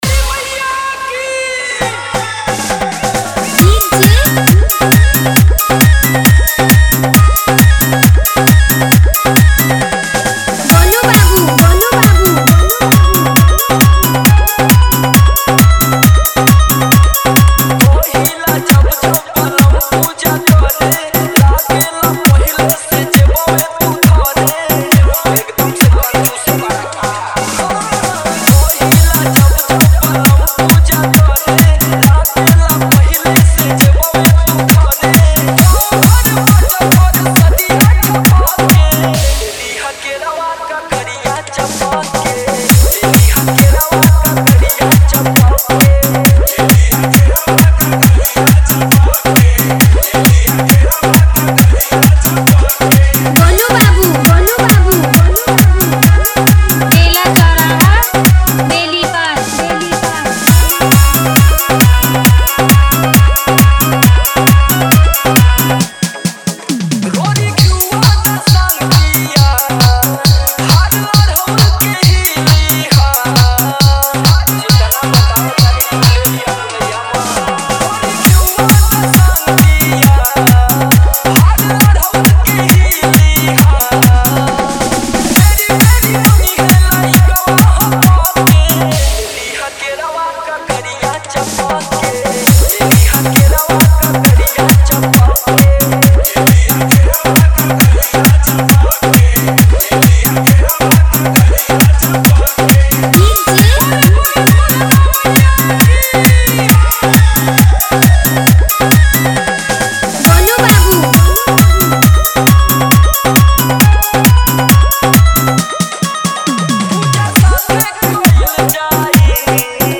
Bhakti Dj Songs